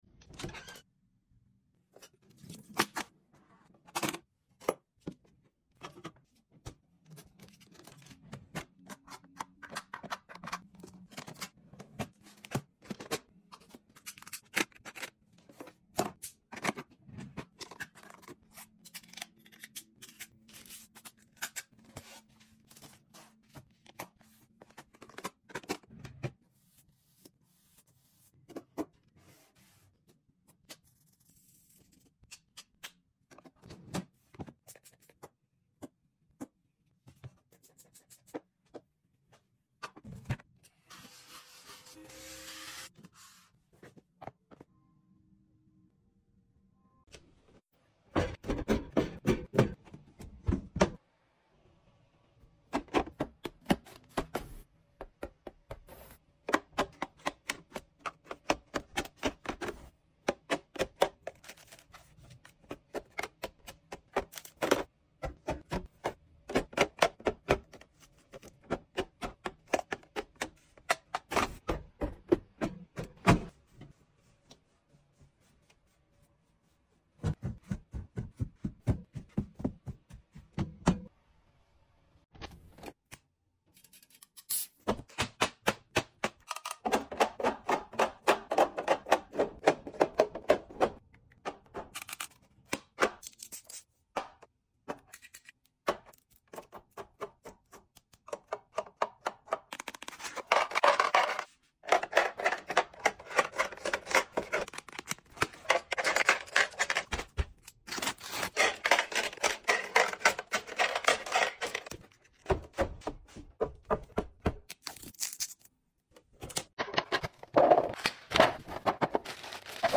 ASMR Office refresh, closet organization,